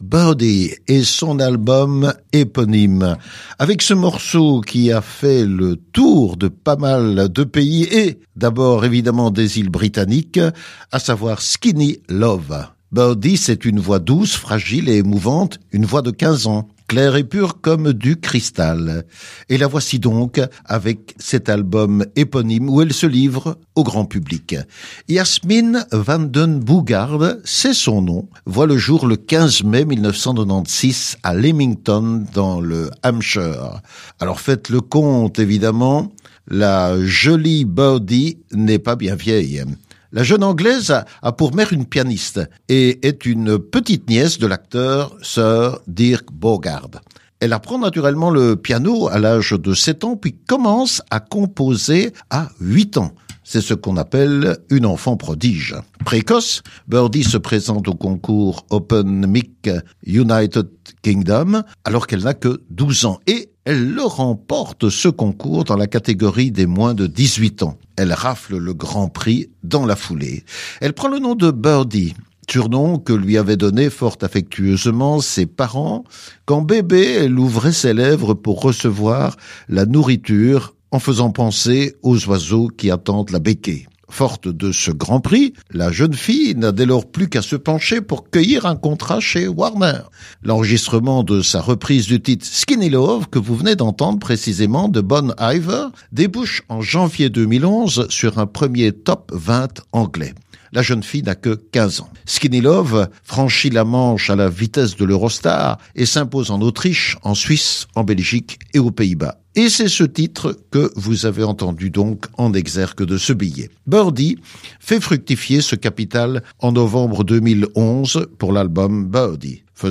chanteuse folk britannique